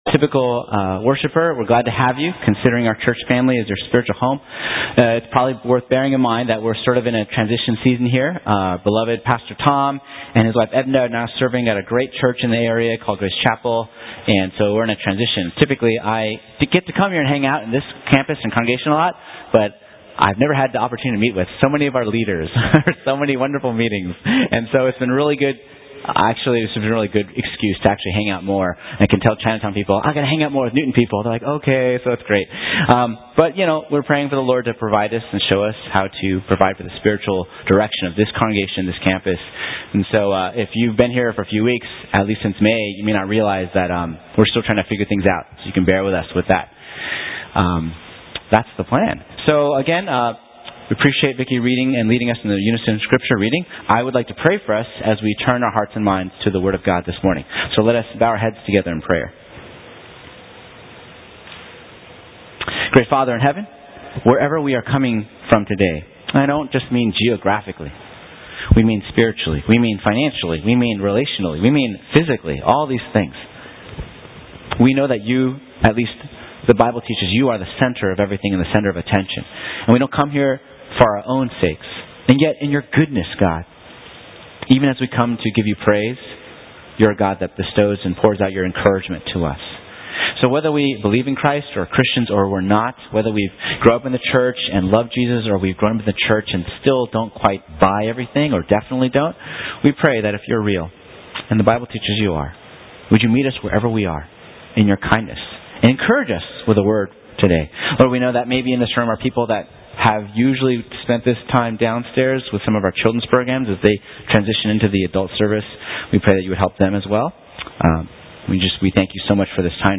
Sermons - Page 41 of 74 | Boston Chinese Evangelical Church